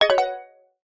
zapsplat_multimedia_alert_glassy_mallet_001_26395